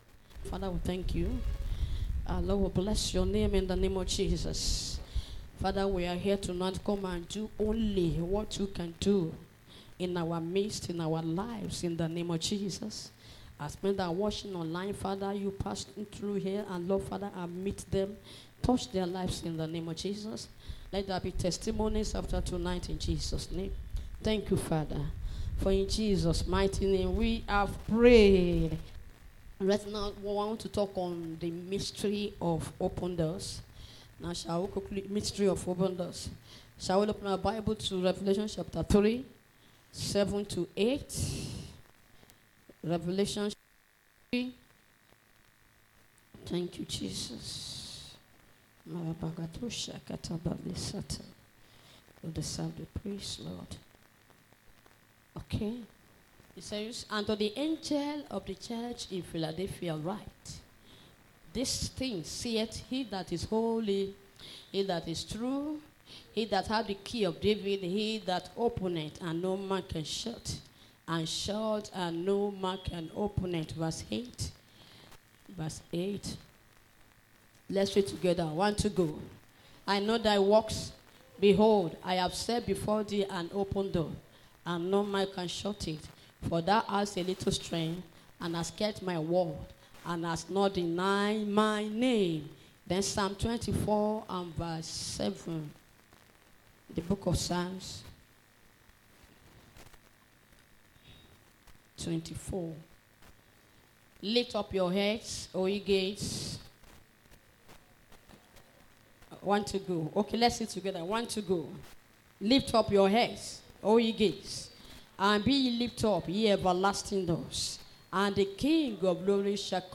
Service Type: Wednesday Service